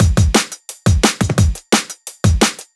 Drum Loops (10).wav